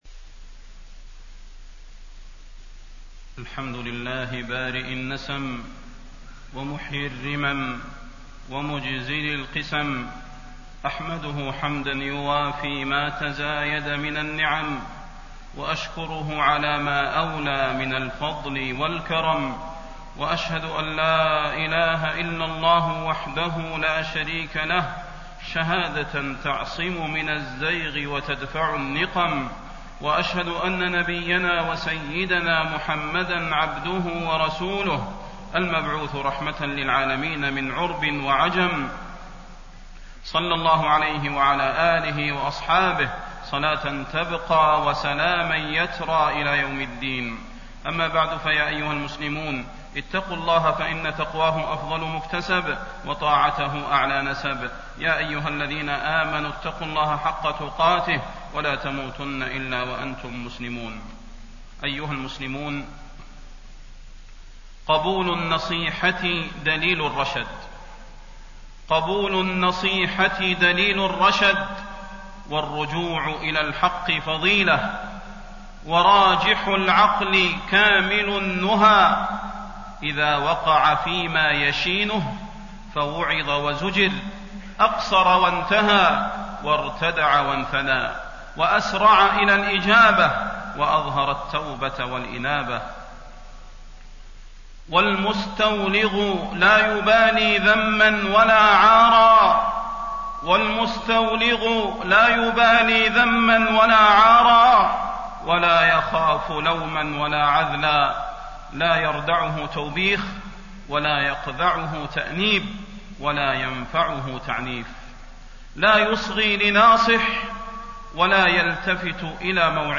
تاريخ النشر ٢٢ صفر ١٤٣٤ هـ المكان: المسجد النبوي الشيخ: فضيلة الشيخ د. صلاح بن محمد البدير فضيلة الشيخ د. صلاح بن محمد البدير النصيحة وفضل قبولها